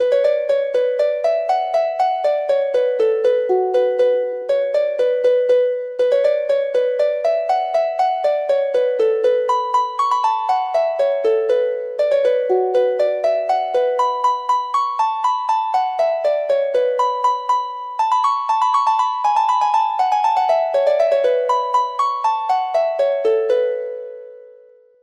Traditional Turlough O Carolan Planxty Drew Harp version
Harp  (View more Easy Harp Music)
Traditional (View more Traditional Harp Music)